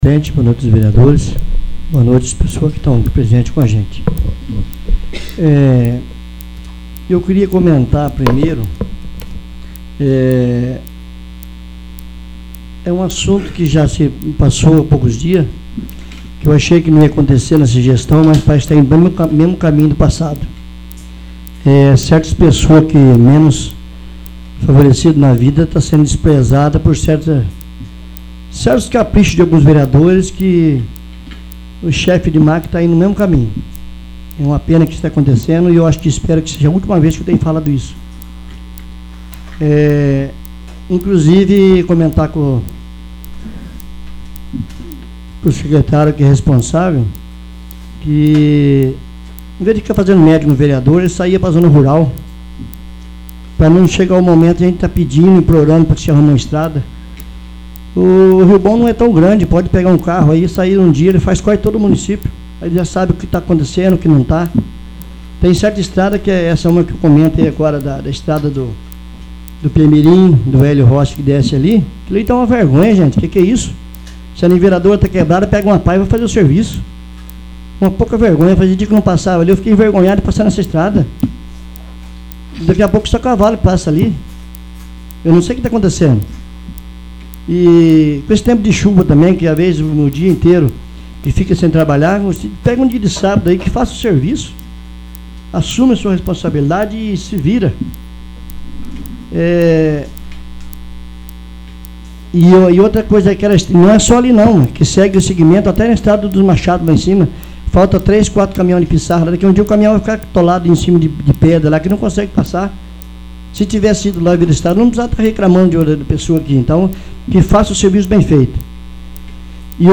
9º. Sessão Ordinária